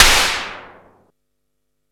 SIMMONS SDS7 7.wav